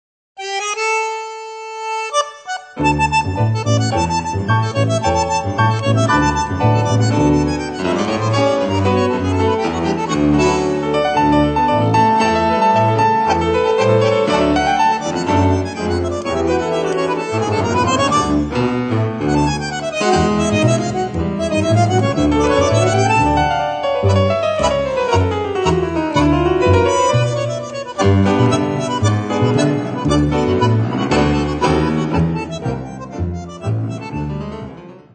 Einige gern gehörte/ getanzte Milonga-Stücke